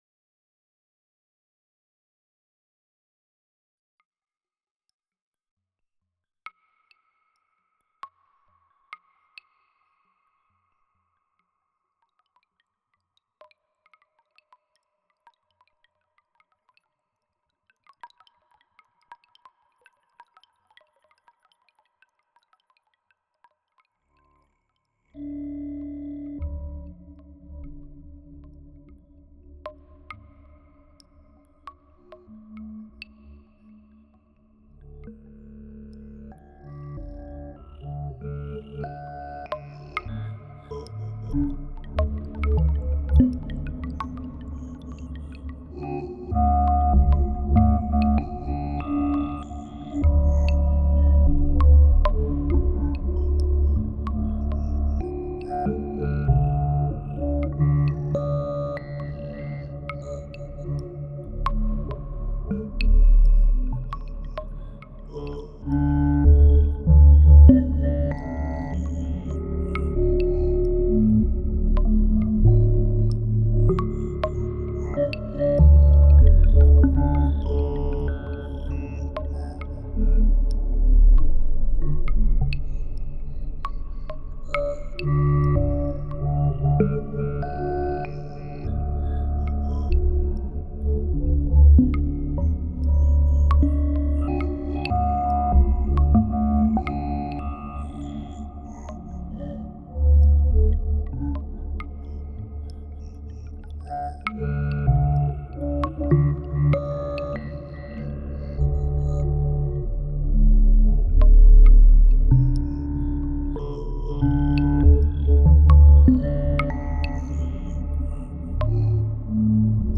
Small demo soundscape/texture.
– eurorack modular synth
– Kurzweil KSP8 effects processor
– Cwejman MMF-6 (as a SINE osc)
– MOTM E350 (as an LFO)
– MakeNoise ModDemix (for sideband)
– Cwejman RES-4 (for vocal effects)
– Toppobrillo Sport Modularor (semi random modulator)
Listen with a good headphone or a good pair of loudspeakers to enjoy the very low bass part !